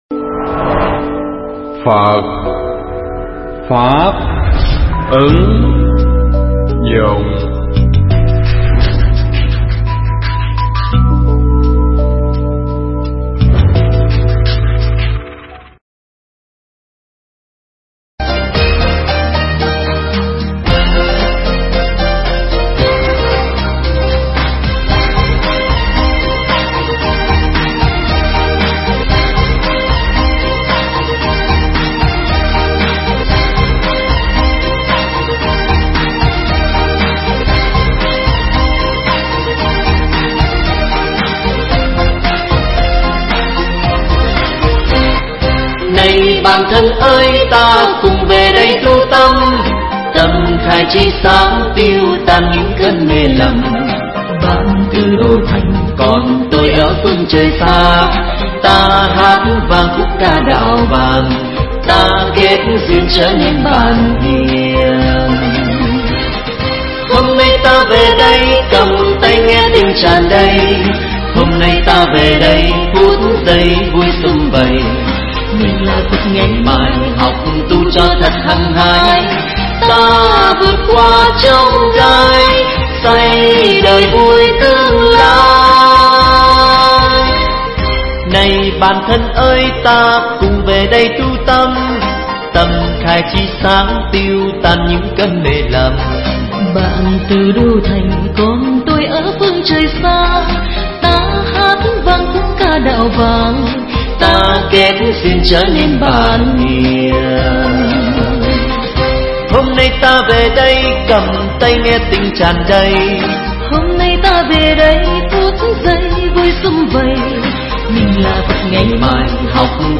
Tải mp3 thuyết pháp Tu Học
pháp thoại
thuyết pháp tại chùa Long Viễn (Tp.Vĩnh Long)